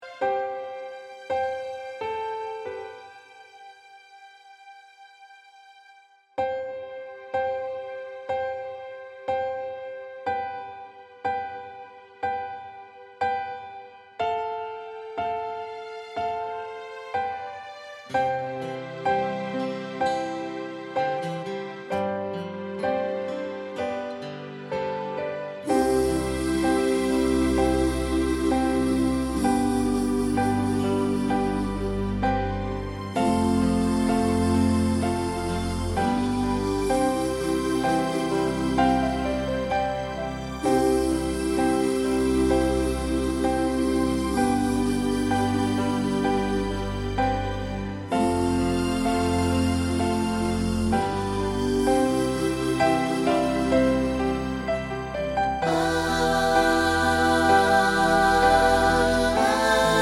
Live Version Easy Listening 3:36 Buy £1.50